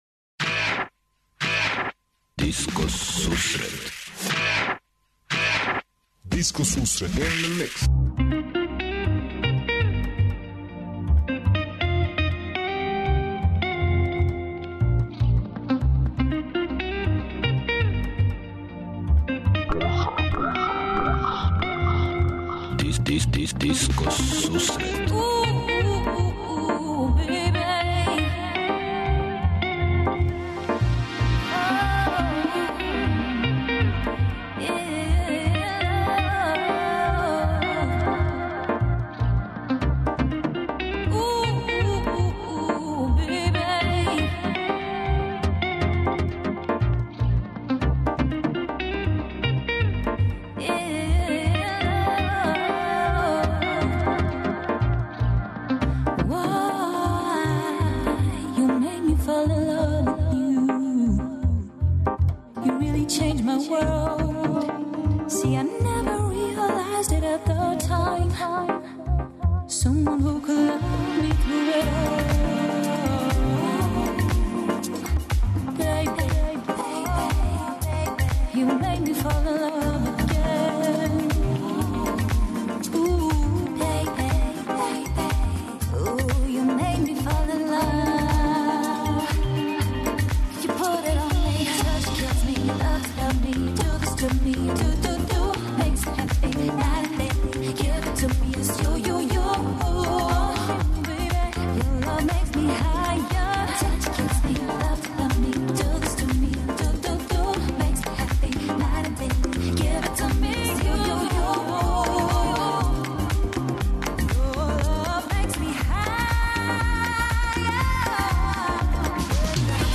Диско сусрет је емисија посвећена најновијој и оригиналној диско музици у широком смислу, укључујући све стилске утицаје других музичких праваца - фанк, соул, РнБ, итало-диско, денс, поп. Непосредан контакт са слушаоцима уз пуно позитивне енергије је основа на којој ова емисија гради забаву сваке среде.